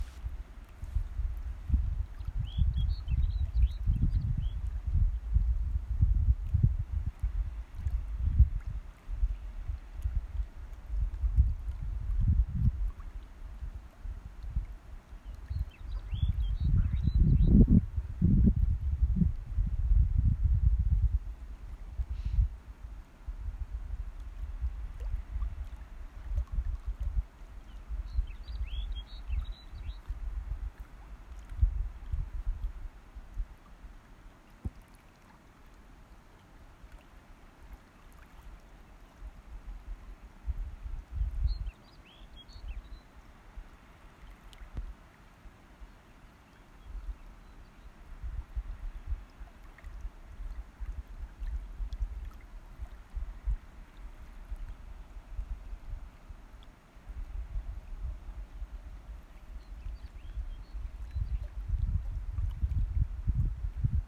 Abstieg über Schneefelder und Wasserfälle zum See und am See entlang, der wie ein Spiegel in der rauen Bergwelt liegt. In der Ferne rauschen die Wasserfälle, kleine Wellen schwappen im leichten Wind an den Strand, Schneeammern rufen in die glasklare Luft hinein.
Am-See-mit-Hahnenfuß.m4a